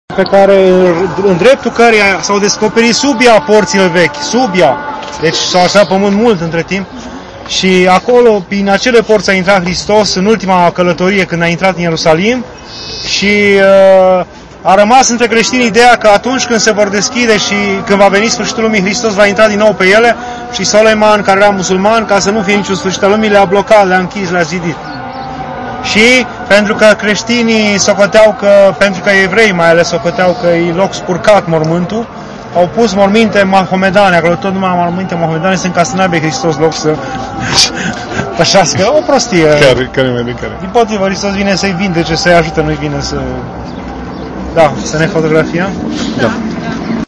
Mănăstirea ,,Domnul a plâns”, despre Poarta de Aur